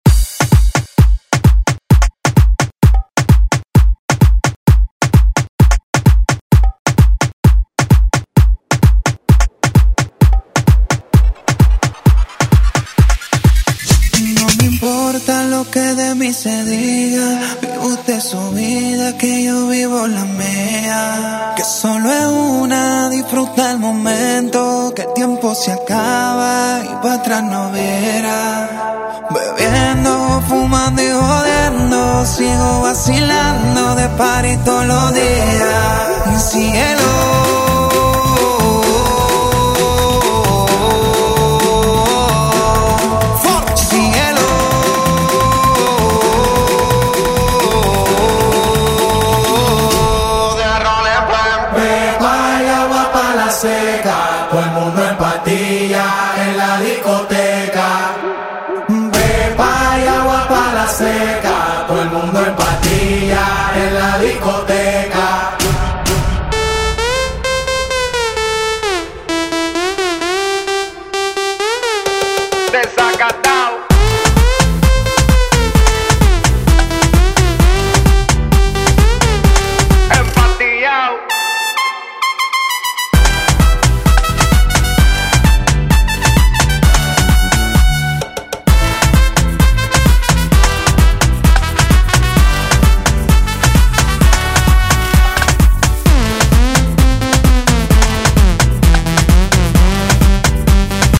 Genres: LATIN , RE-DRUM , TOP40
Clean BPM: 120 Time